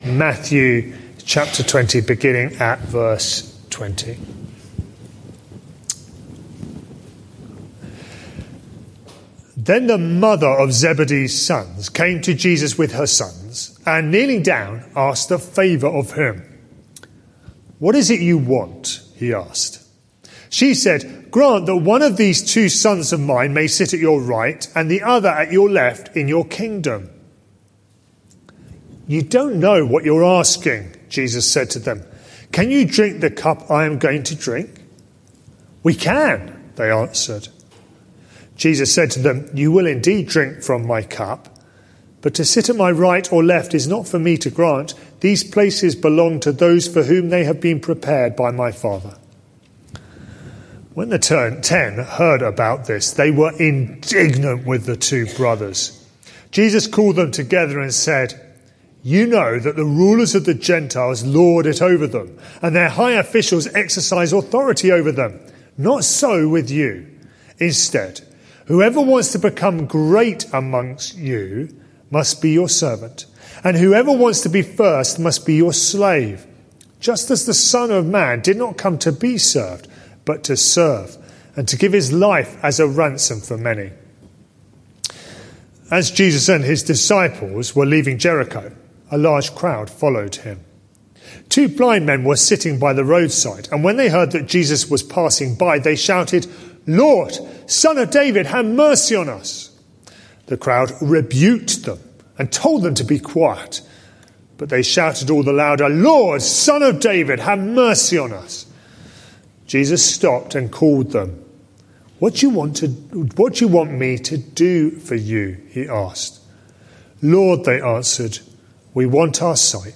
This sermon is part of a series: 23 February 2020, 6:20 pm - Why Do You Call Me Lord, Lord, and Not Do As I Say?